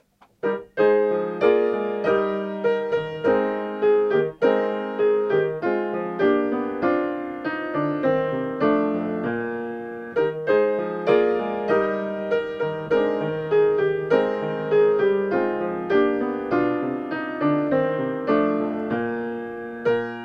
第1主題提示部分(1-56)Es dur
＜＜＜確認のためだけの下手なmp3＞＞＞